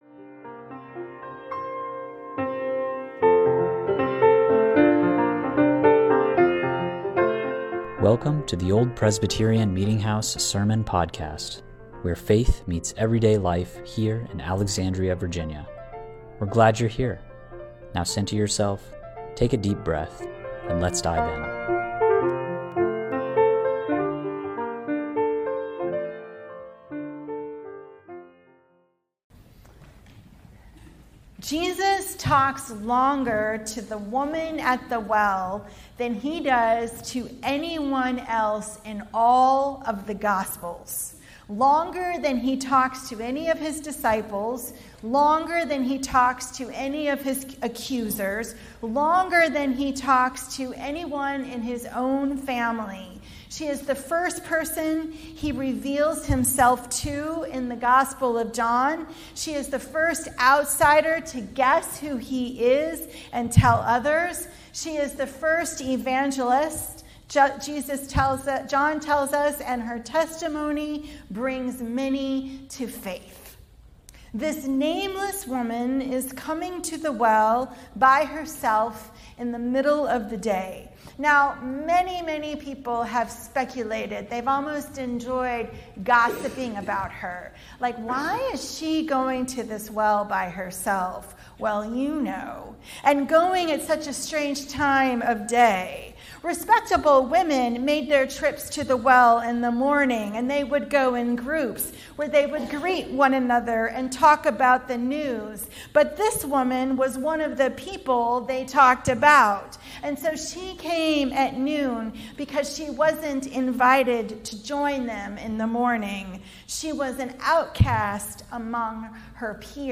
Sunday Sermon Series, March 8, 2026.